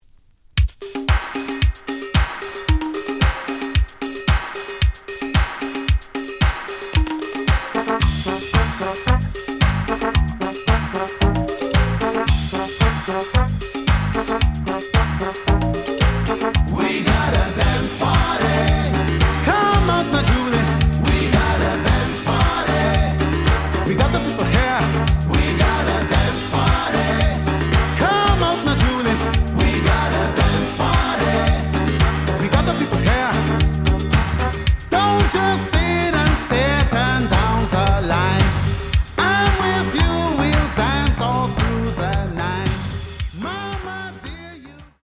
試聴は実際のレコードから録音しています。
●Genre: Reggae